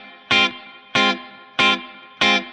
DD_StratChop_95-Fmin.wav